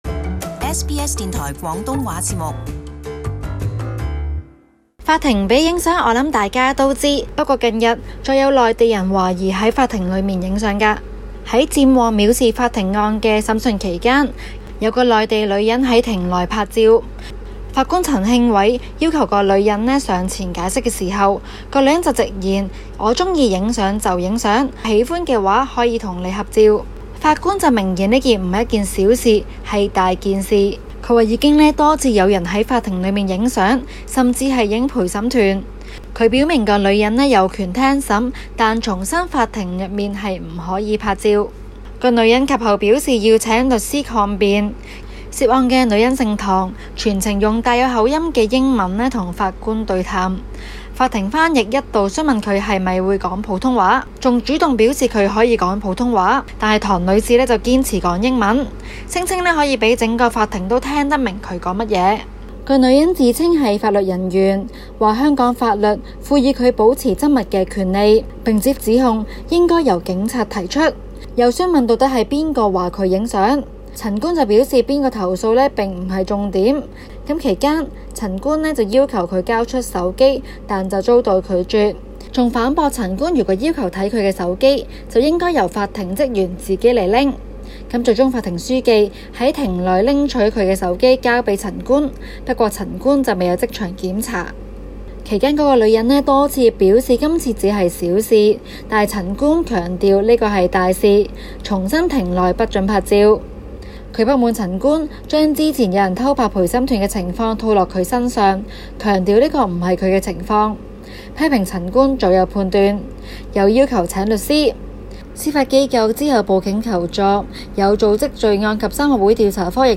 【時事報導】中國外交部回應周澤榮案：無中生有